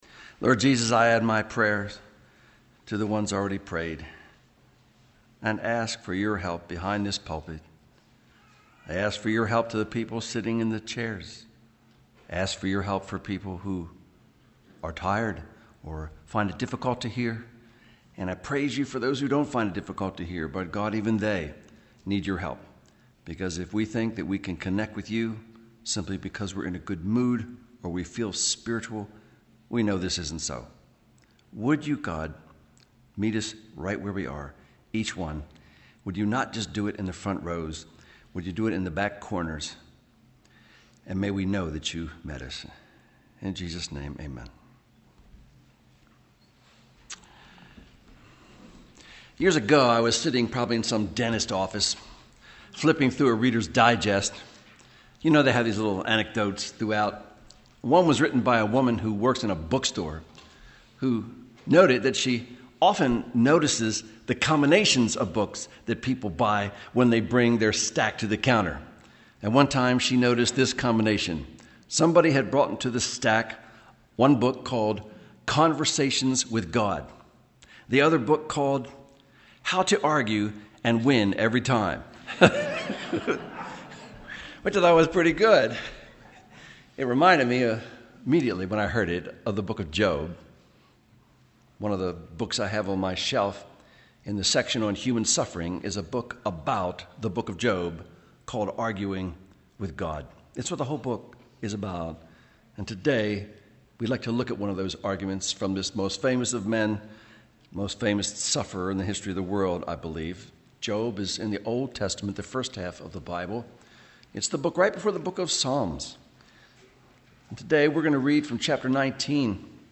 MP3 audio sermons from Brick Lane Community Church in Elverson, Pennsylvania.